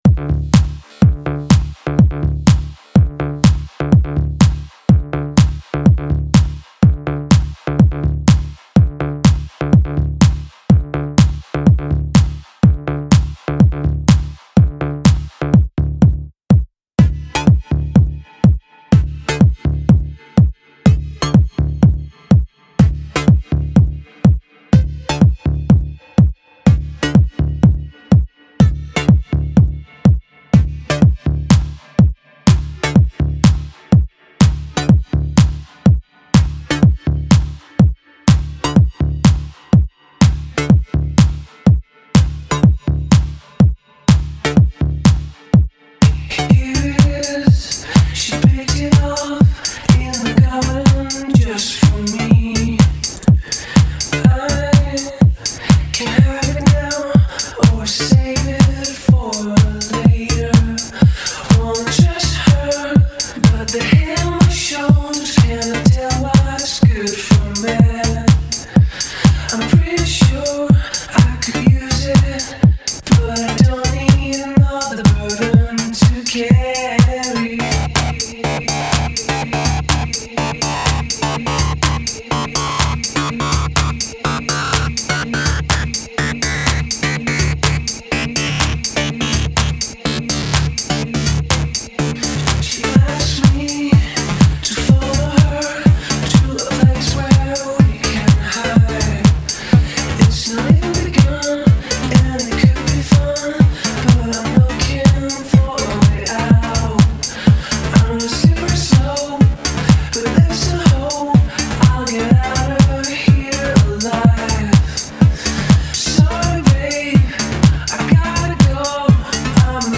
French popsters
remix